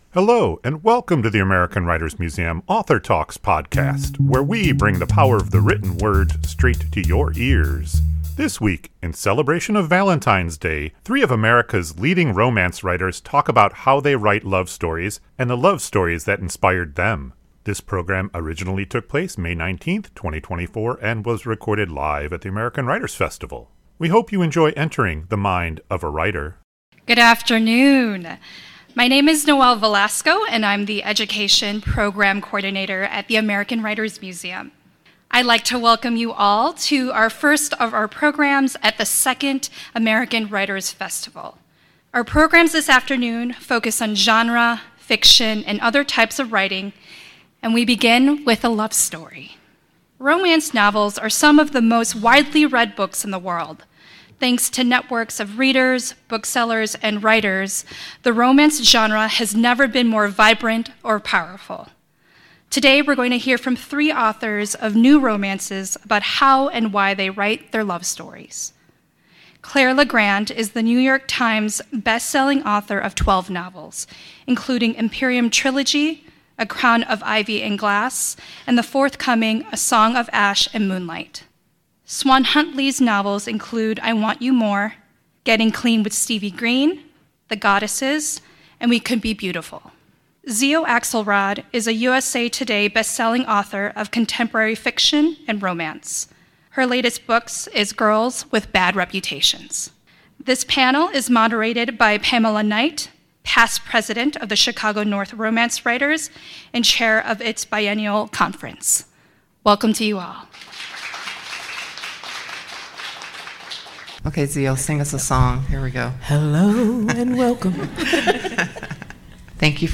This conversation originally took place May 19, 2024 and was recorded live at the American Writers Festival.